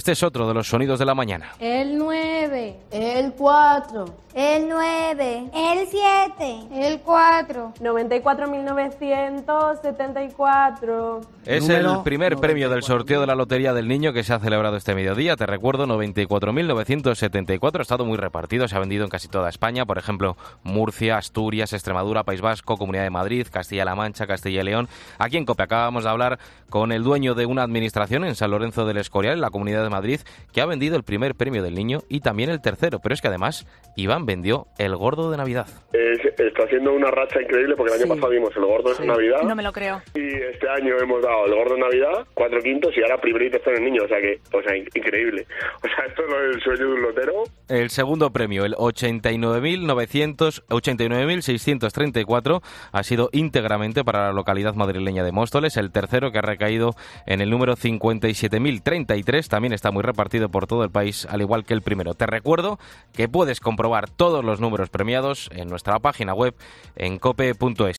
"Está siendo una racha increíble porque el año pasado dimos el Gordo y este año hemos dado el Gordo, cuatro quintos y ahora primer y tercero en el Niño. Increíble", contaba visiblemente contento en los micrófonos de 'Fin de Semana'.